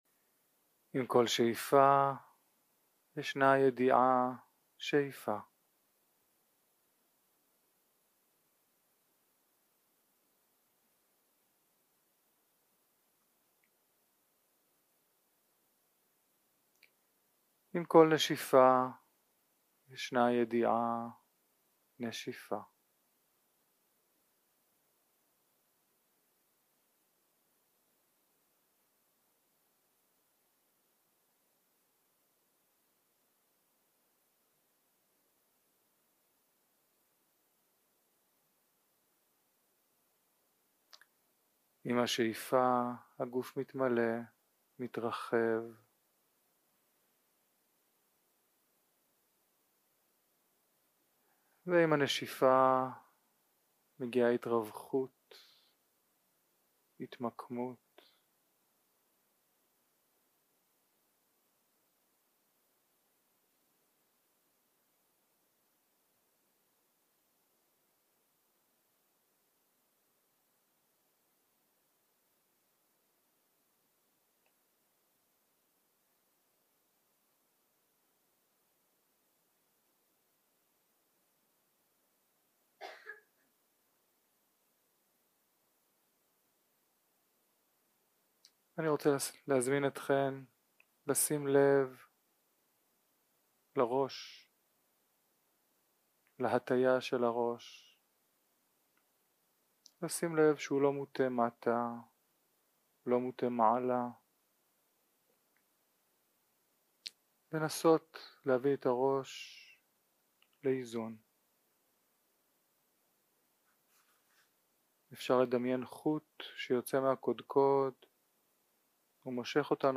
יום 2 - הקלטה 3 - צהרים - הנחיות למדיטציה - תשומת לב לגוף ולנשימה 0:00 0:00 סוג ההקלטה: Dharma type: Guided meditation שפת ההקלטה: Dharma talk language: Hebrew